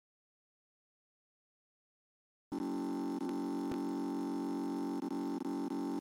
Blue Screen Sound Effect
blue-screen-sound-effect.mp3